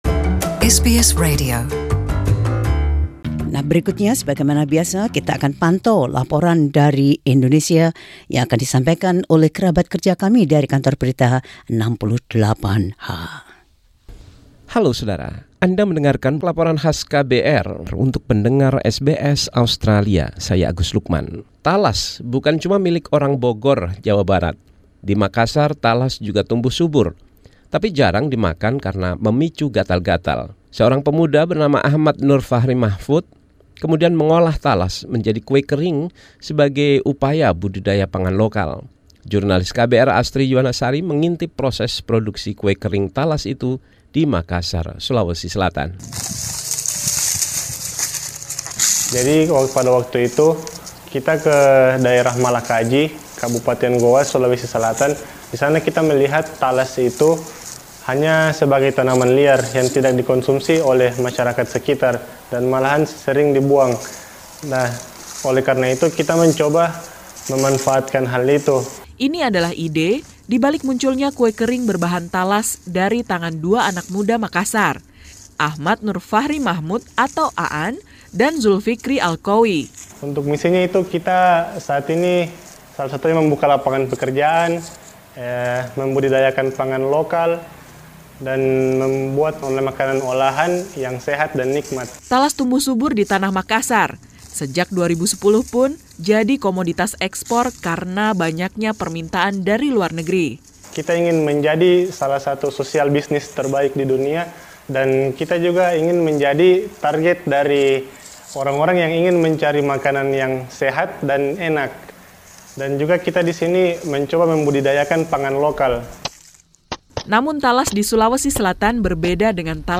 KBR 68H Report: Alms in Every Bite.